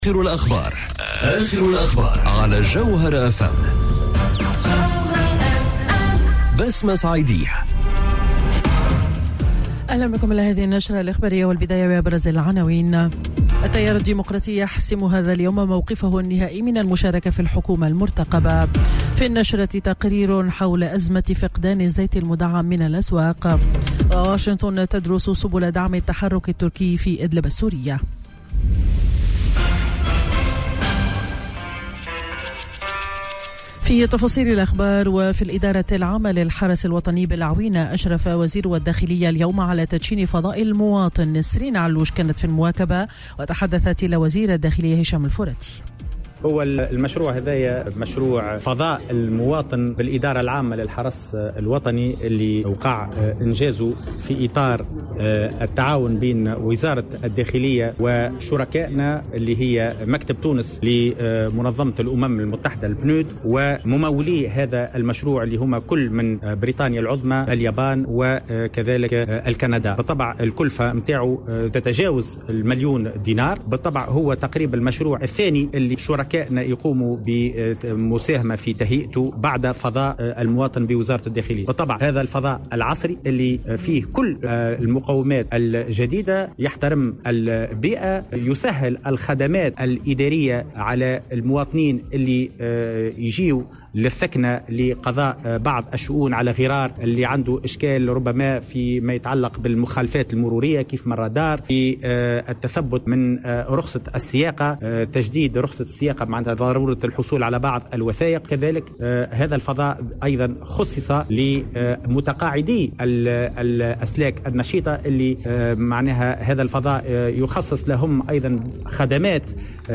نشرة أخبار منتصف النهار ليوم الخميس 13 فيفري 2020